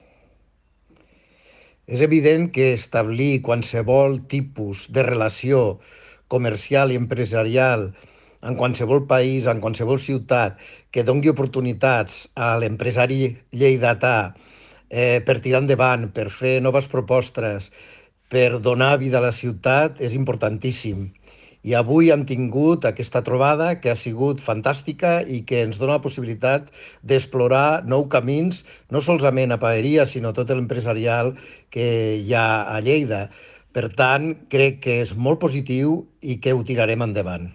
tall-de-veu-del-tinent-dalcalde-paco-cerda-sobre-l2019agermanament-que-lleida-prepara-amb-el-districte-xines-de-gulou